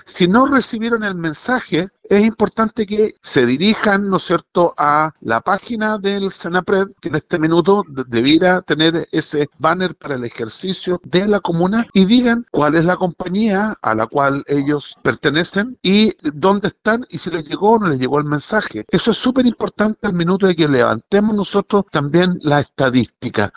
Ambiente sonido… Este será el sonido que deberá escucharse en todos los celulares que sean compatibles, a lo largo y ancho de la comuna de Osorno, en una prueba del Sistema de Alerta de Emergencia (SAE), programado para este jueves 27 de agosto a las 11 de la mañana.